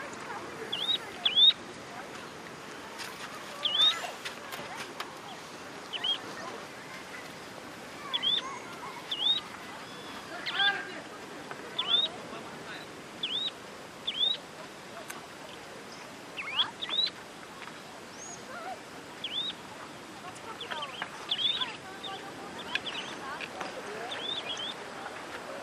O canto da batuíra-costeira emite um chamado kleet suave, mas o canto territorial é alto e retumbante.
Canto da batuíra-costeira
canto-da-batuira-costeira.mp3